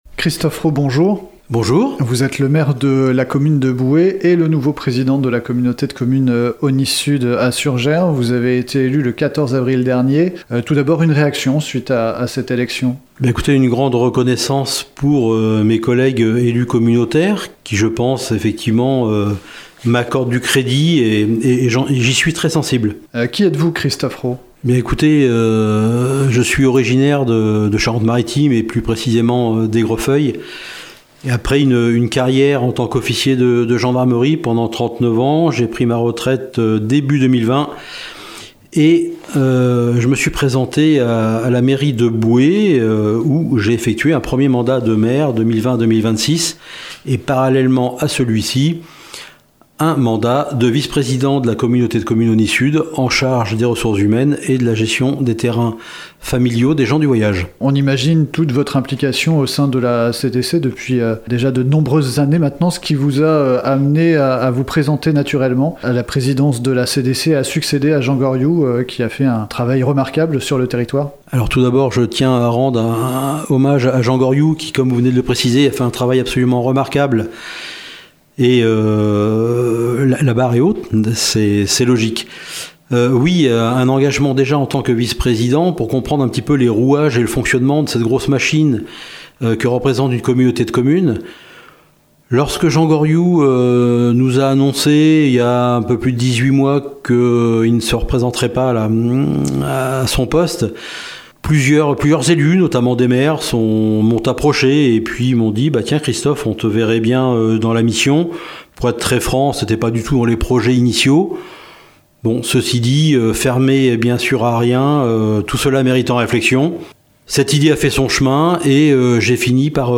Rencontre avec le nouveau président de la Communauté de communes Aunis Sud.